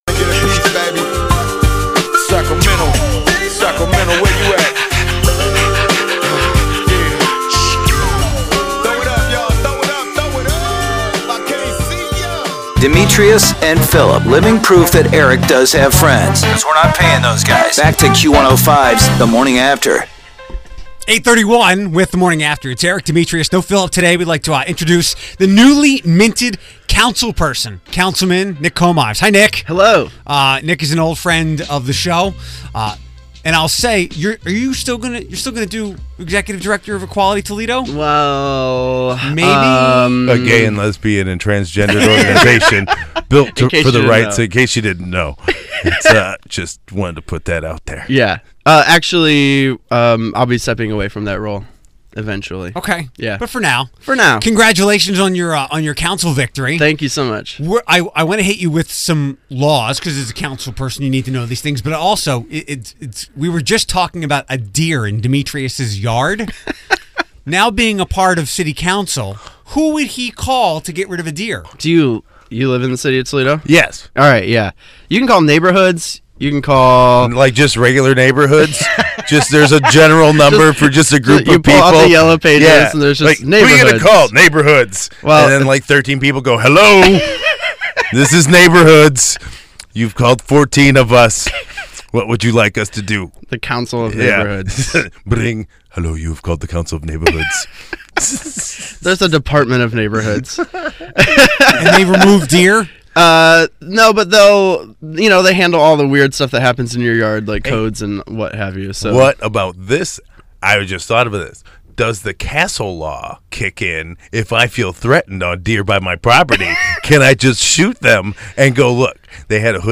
New Toledo city councilman Nick Komives is on with us. Does he know our laws?